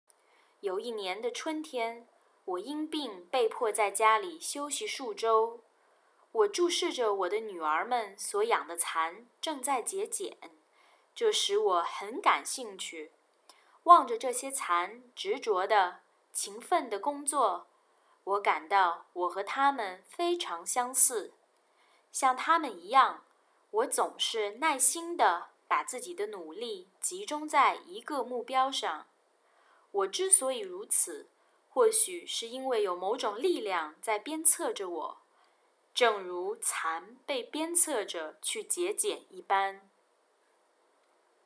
Task 3 Passage Reading
Beijing Sample: